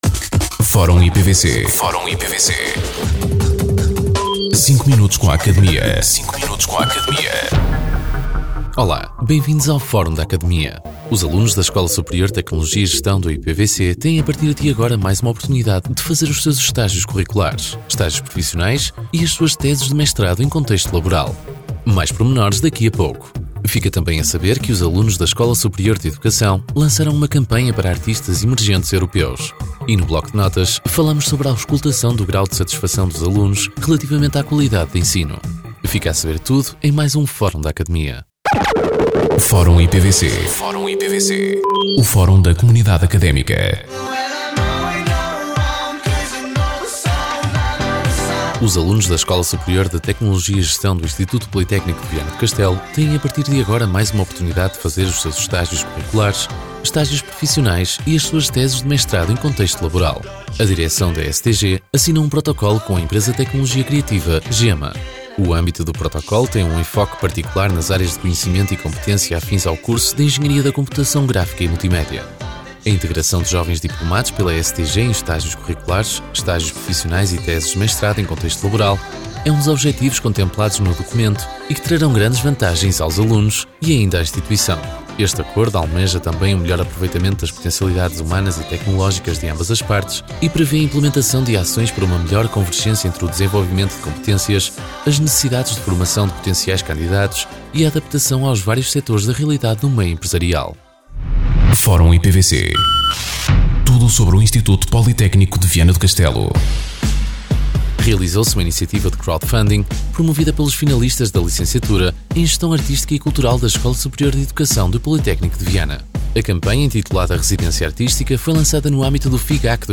Depois do programa “Academia”, surge assim o “Fórum IPVC”, uma rubrica semanal de cinco minutos, em que são abordadas as notícias e factos mais relevantes da nossa instituição.
Entrevistados: